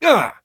pain_1.ogg